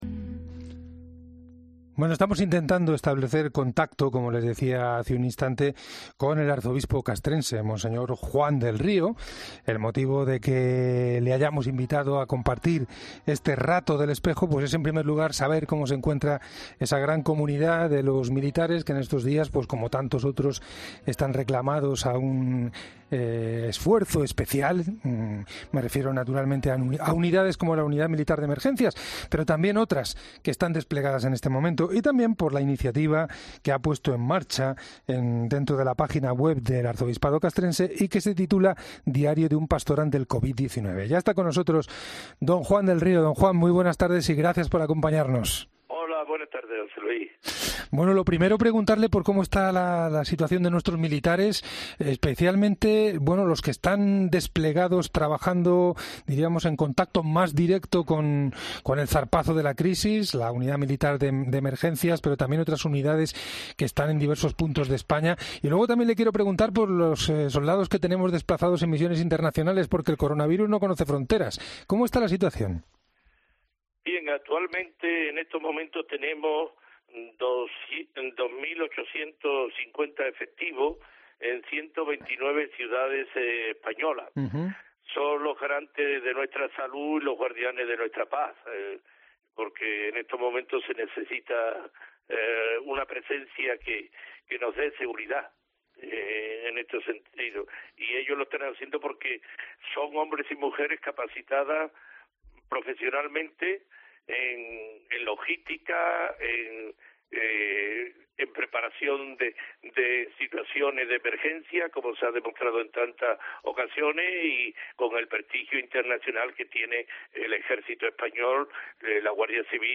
El arzobispo castrense mons. Juan del Río explica en El Espejo cómo se encuentran los militares en esta situación de lucha contra el coronavirus